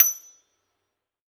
53s-pno27-F6.aif